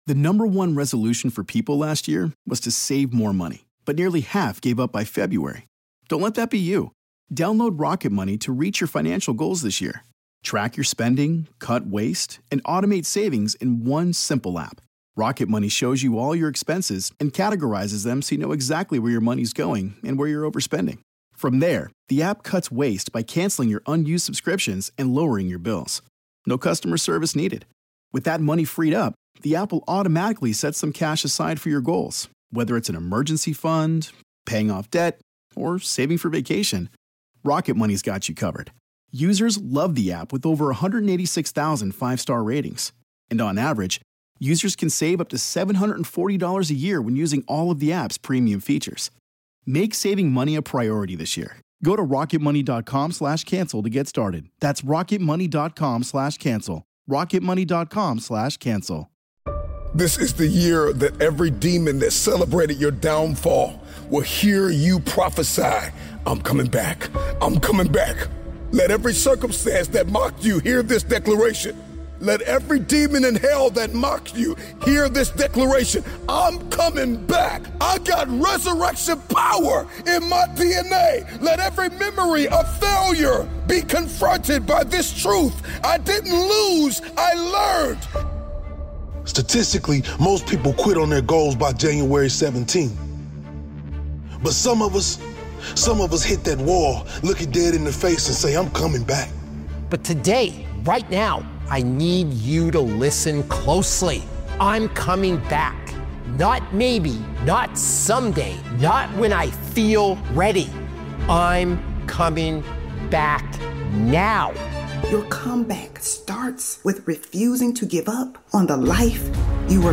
Powerful Motivational Speech